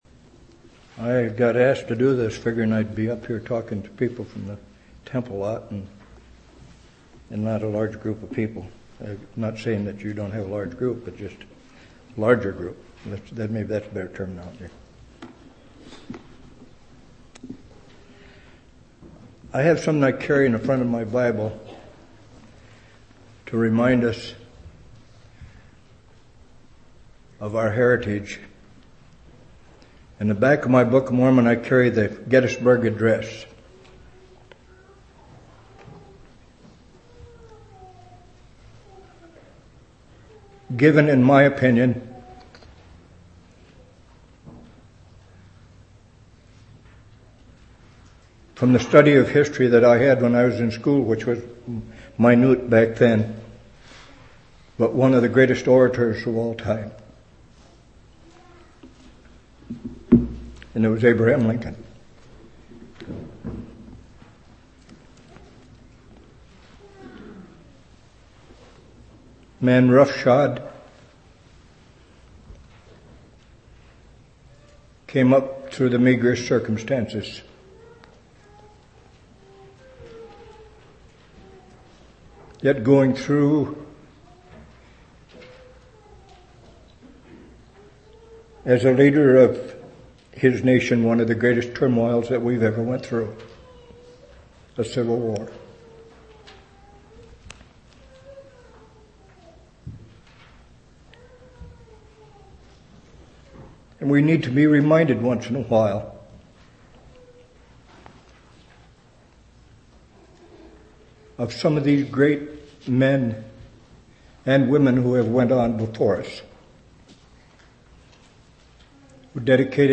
2/27/1994 Location: Temple Lot Local Event